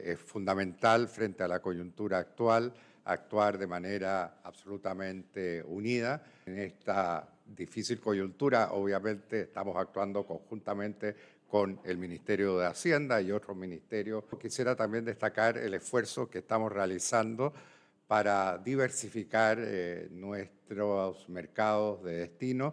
Frente a este escenario, el canciller, Alberto Van Klaveren, indicó que hay que actuar unidos en tiempos de coyuntura.